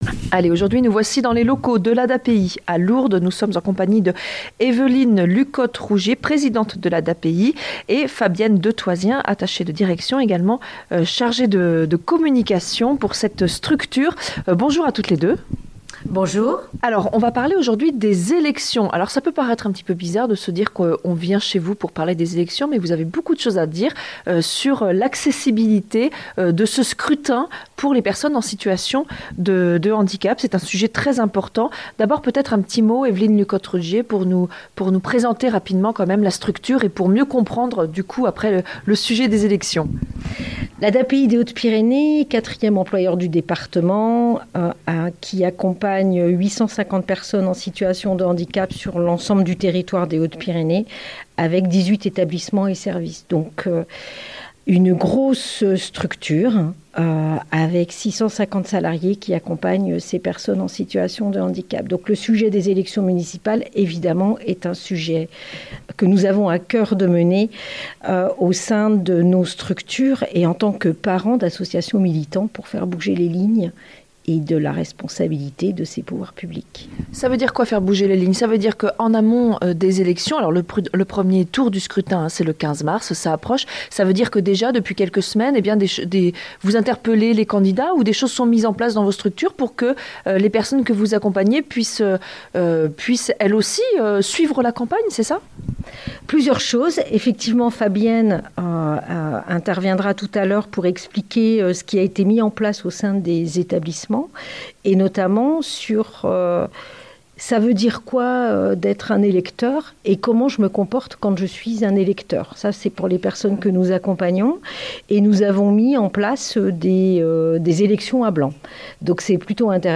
Interview et reportage